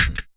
Snr Dblclik.wav